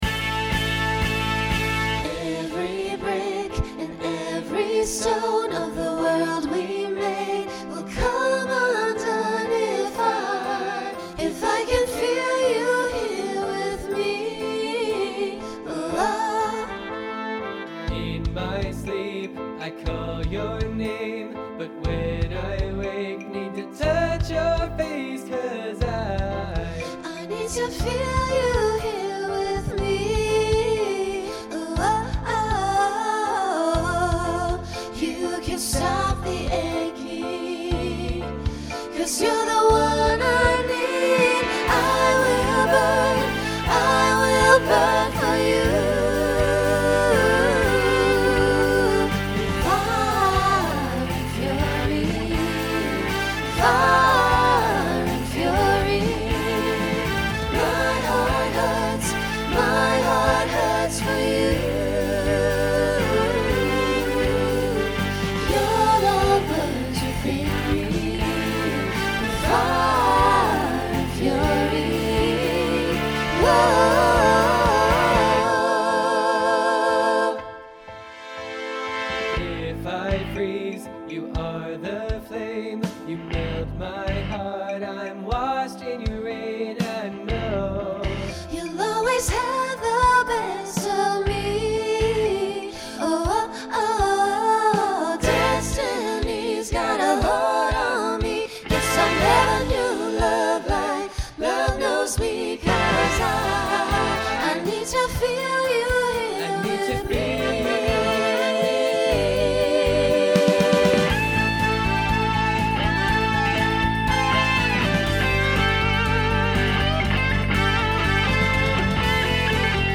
Genre Rock Instrumental combo
Mid-tempo Voicing SATB